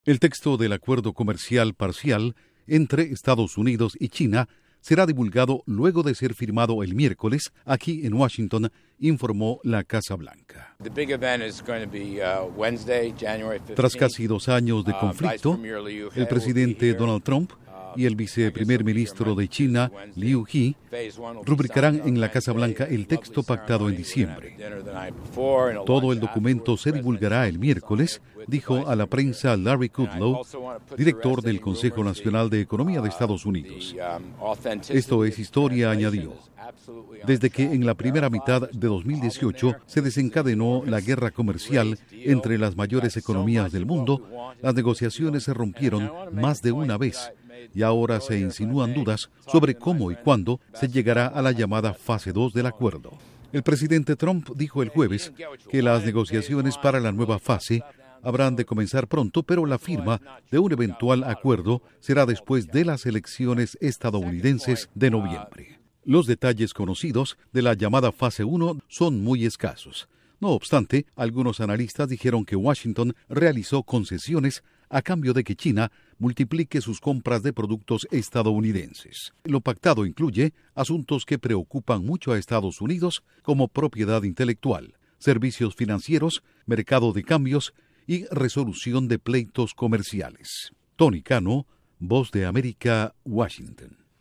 Duración: 1:34 Con declaraciones del Larry Dudlow/ Consejo Nacional de Economía de Estados Unidos